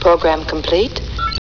(Computervoice: program complete...)